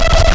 Player_UI [105].wav